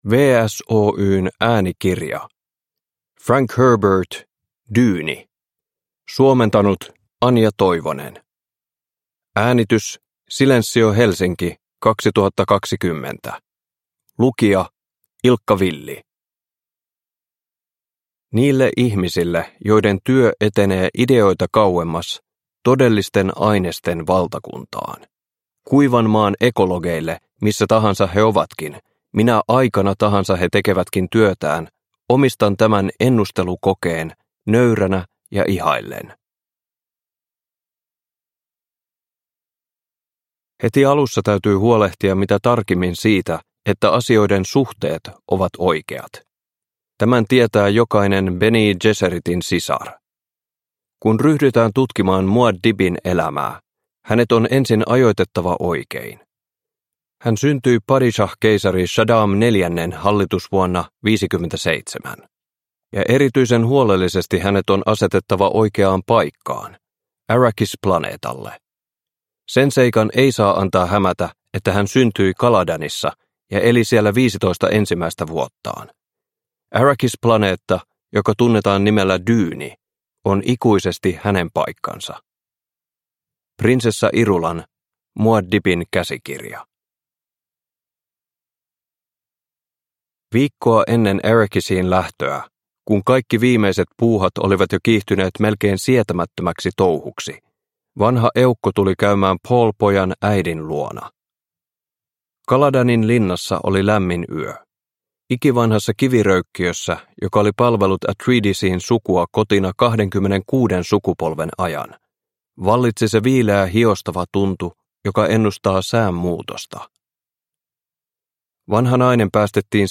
Dyyni – Ljudbok – Laddas ner